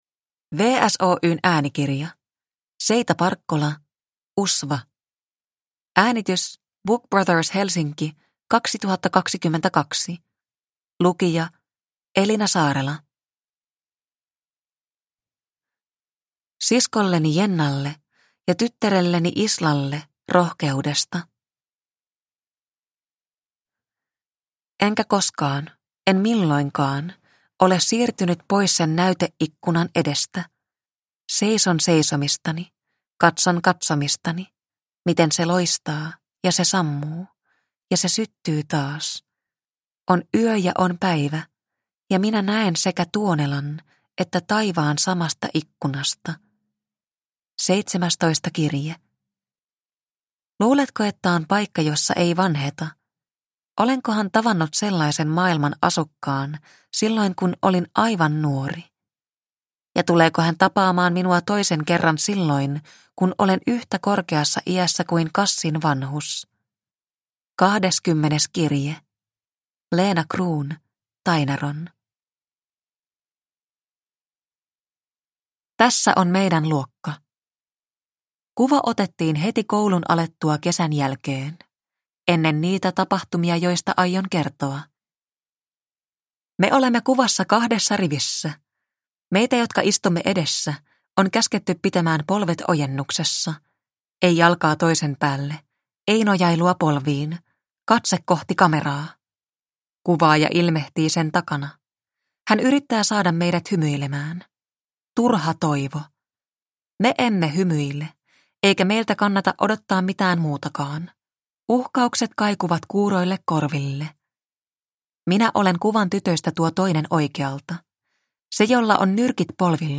Usva – Ljudbok – Laddas ner